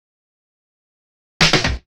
Fill 128 BPM (12).wav